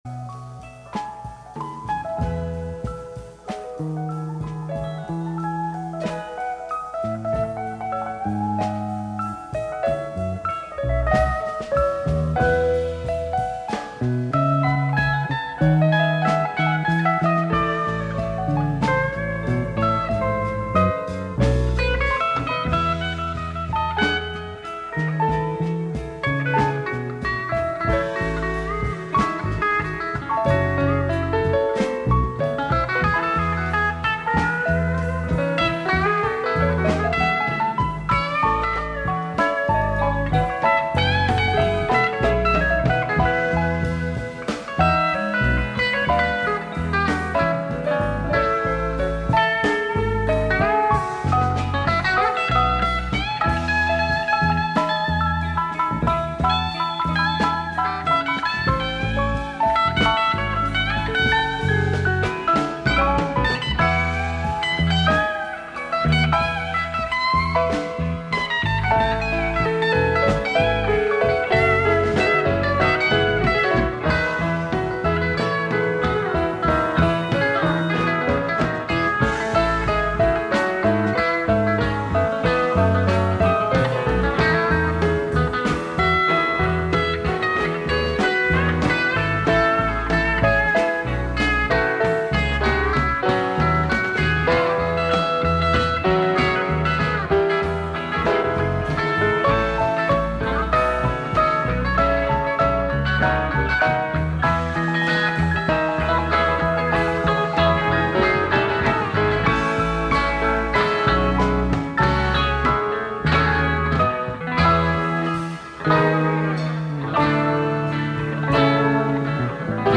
guitar solo
recorded in the Orpheum Theater, San Fransisco, 1976